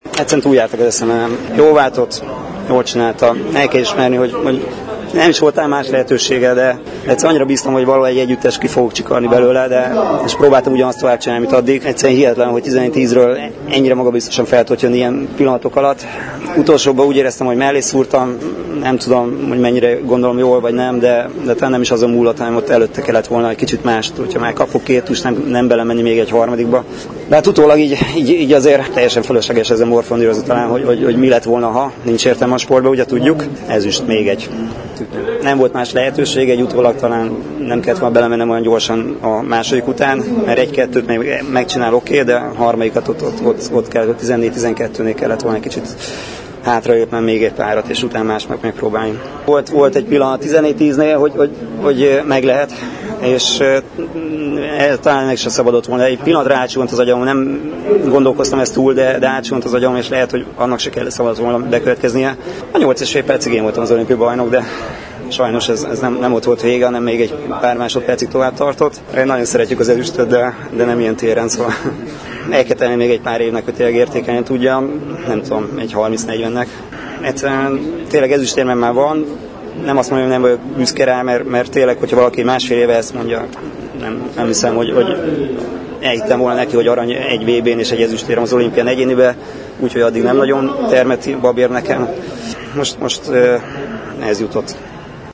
Megremegett a keze Imre Géza párbajtőrözőnek és nem tudott élni a lehetőséggel a riói olimpia döntőjében. 8 és fél percig a magyar vívó volt az olimpiai bajnok, de a dél-koreai Park Sangyoung 14-10-nél sem adta fel és megoldotta a lehetetlennek tűnő feladatot, egymásután öt tust vitt be. Imre Géza olimpiai ezüstérmes lett, és a díjkiosztó ünnepség után csalódottan nyilatkozott: